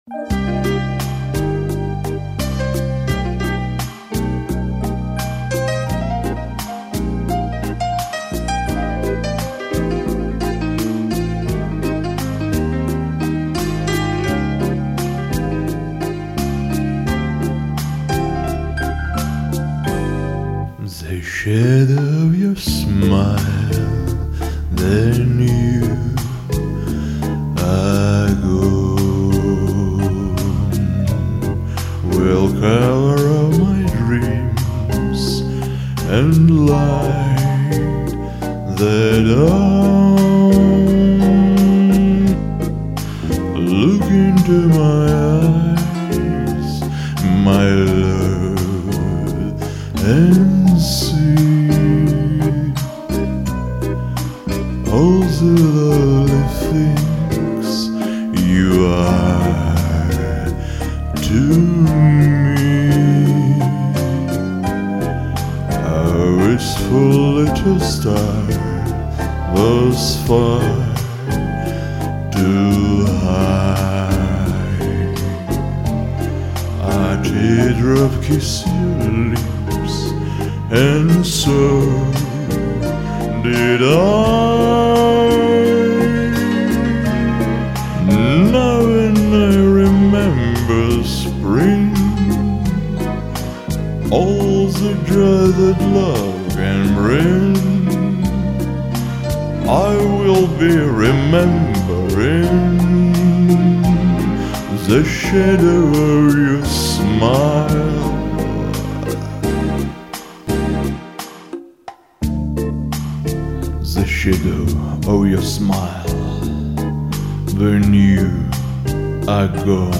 Правда тембрально оба хороши!